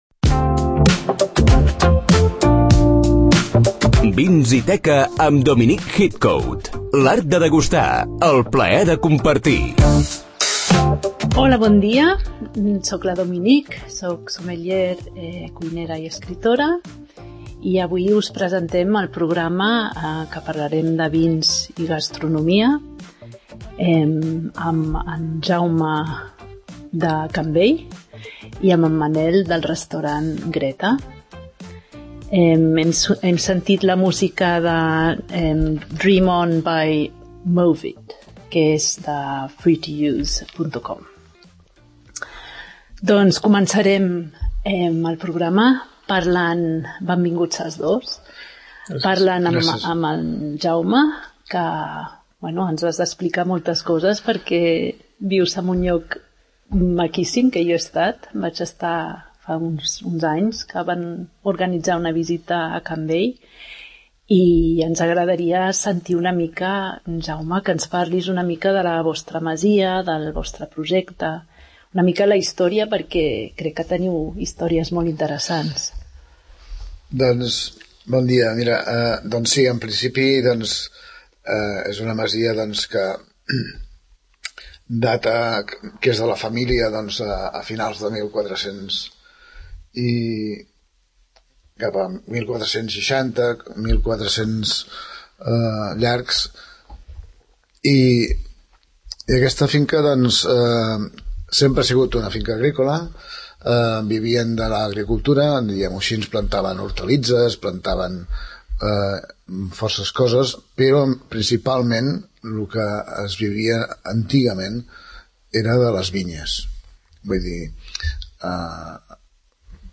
Tot aix� acompanyat de bona m�sica i moltes ganes de compartir, aprendre i degustar.